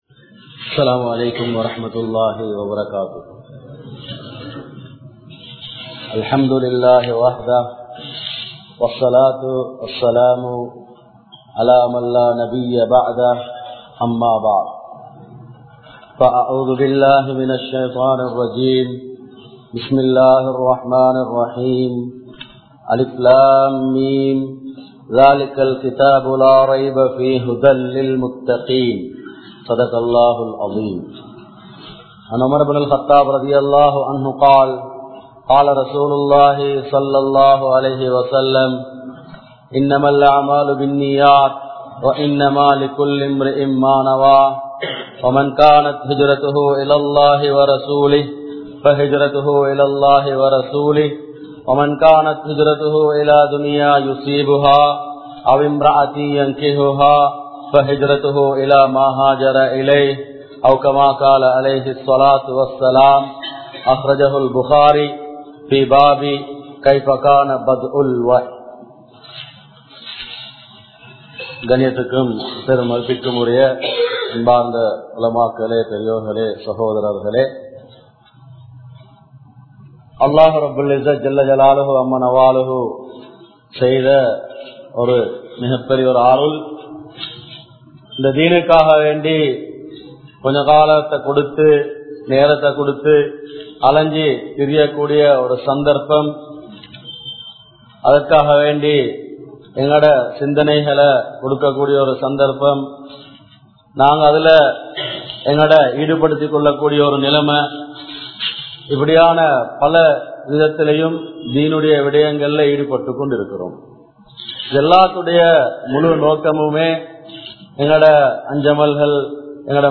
Uruthiyaana Emaan! (உறுதியான ஈமான்!) | Audio Bayans | All Ceylon Muslim Youth Community | Addalaichenai